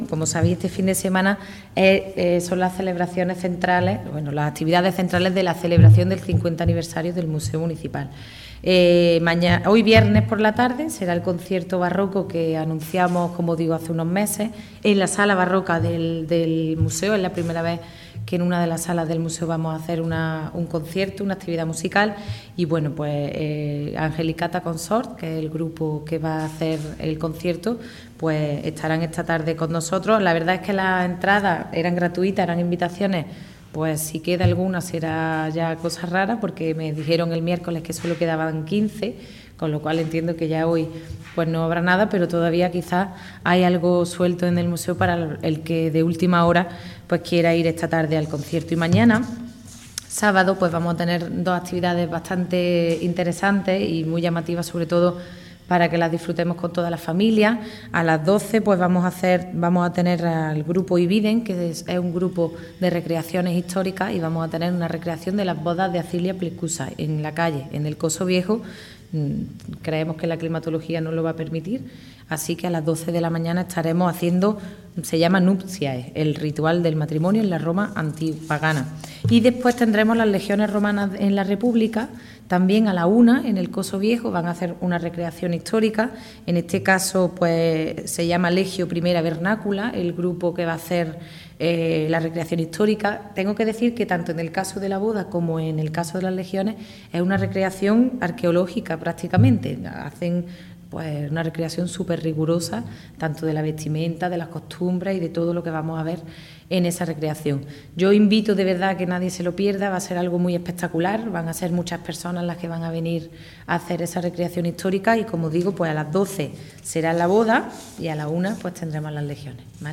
Generar Pdf viernes 2 de diciembre de 2016 El Museo de la Ciudad de Antequera encara este fin de semana la parte central de las actividades conmemorativas de su 50 aniversario Generar Pdf La teniente de alcalde delegada de Cultura, Ferias y Patrimonio Histórico, Ana Cebrián, informa del desarrollo entre hoy viernes y mañana sábado de algunas der las actividades más importantes del programa que conmemora el 50 Aniversario del Museo de la Ciudad de Antequera (MVCA).
Cortes de voz A. Cebrián 1876.73 kb Formato: mp3